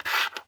Coin Slid Along Wood.wav